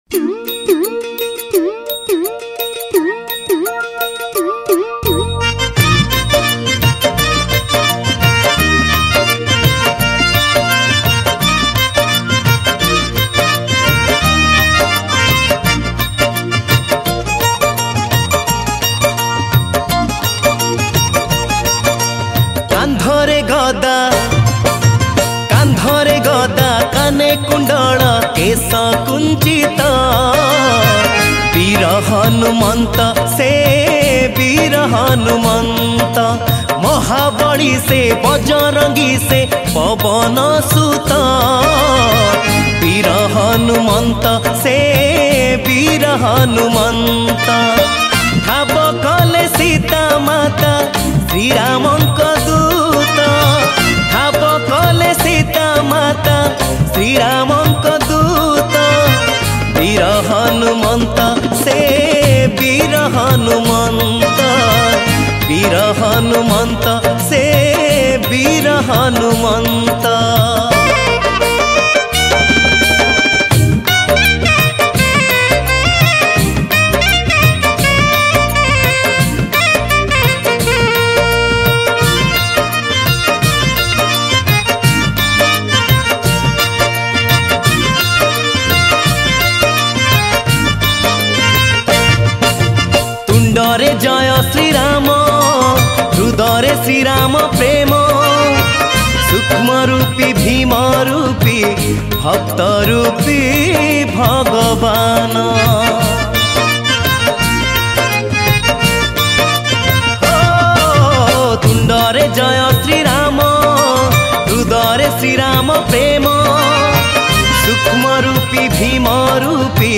Pana Sankarati Special Bhajan